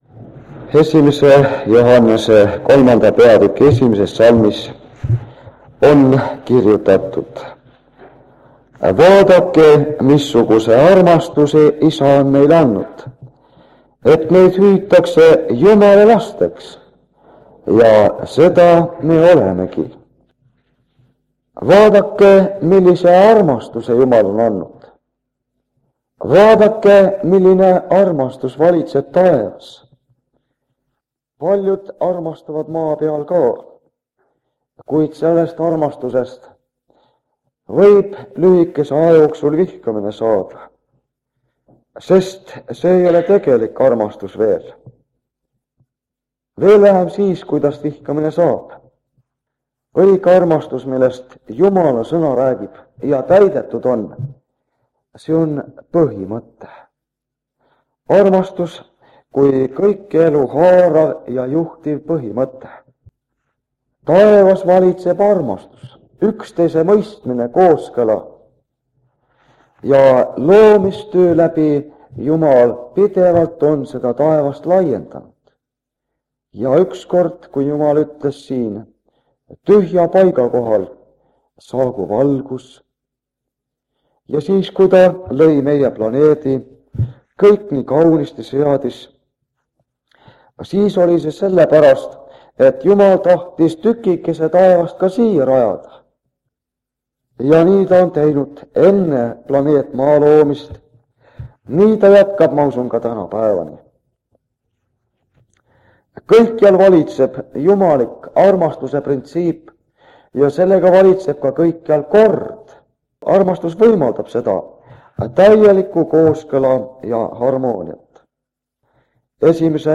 Jutlused
Jutluste minisari Kingissepa adventkoguduses 1978 aastal.